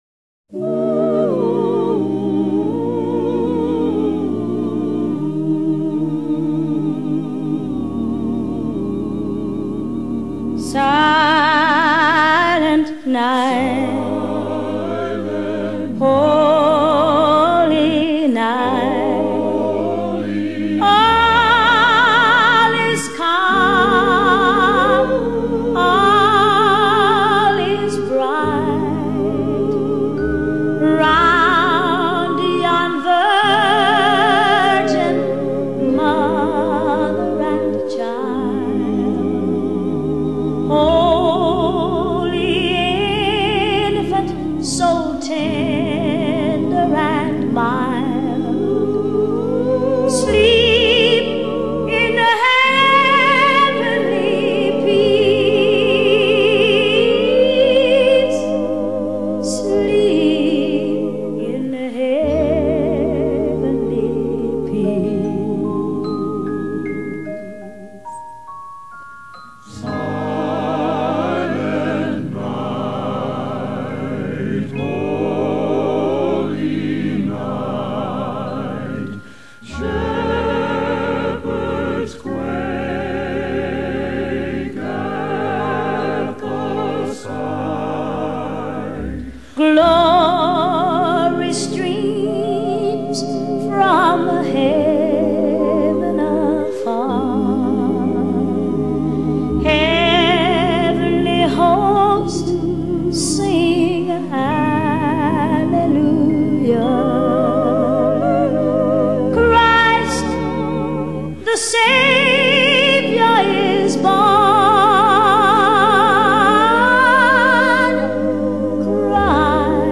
风格流派：Jazz